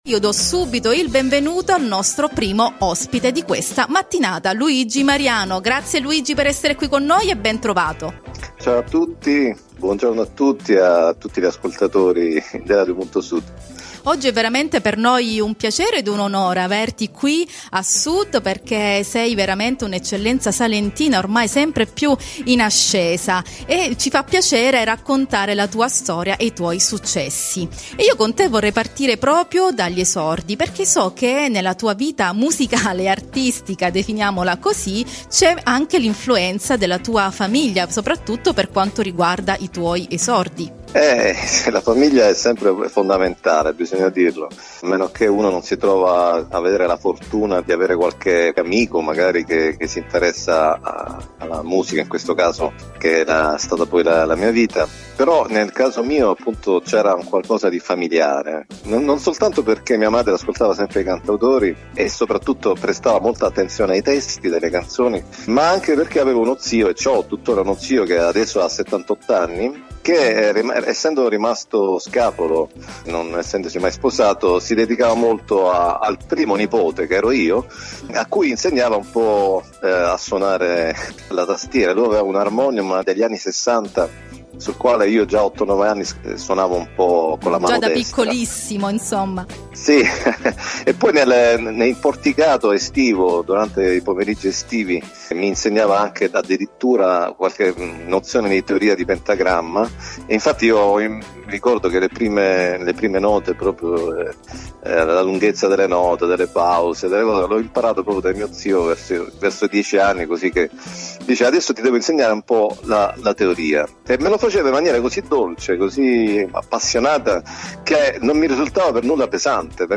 Intervista a RADIO PUNTO SUD nel programma “A sud”